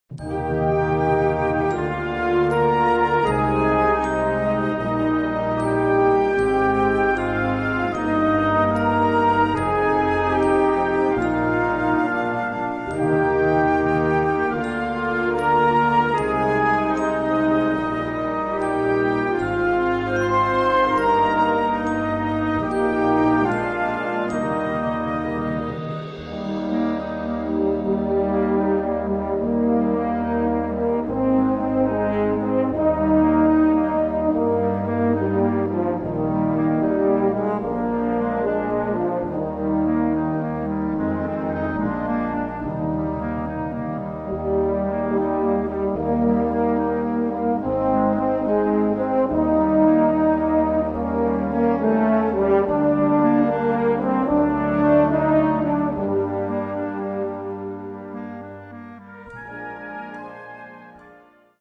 Gattung: Weihnachtslied
Besetzung: Blasorchester